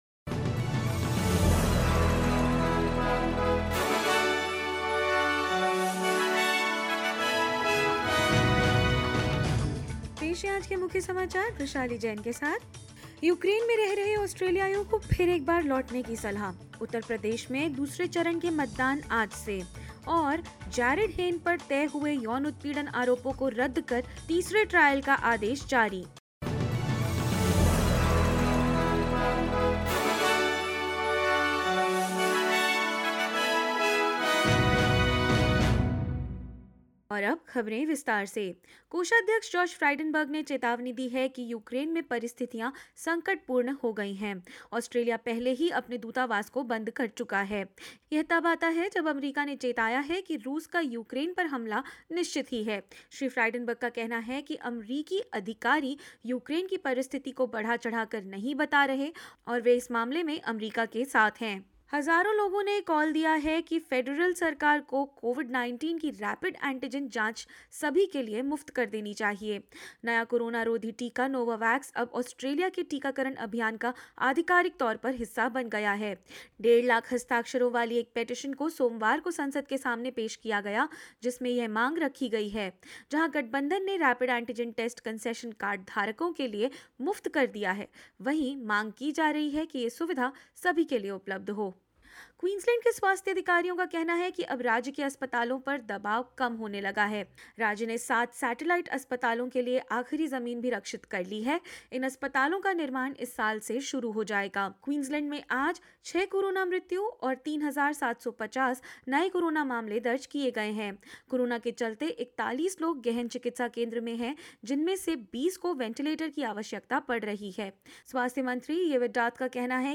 In this latest SBS Hindi bulletin: Federal treasurer Josh Frydenberg calls situation in Ukraine 'dangerous'; Elections underway in the Indian states of Goa, Uttarakhand and Uttar Pradesh; Jailed NRL star Jarryd Hayne's sexual assault convictions quashed and a third trial ordered and more news.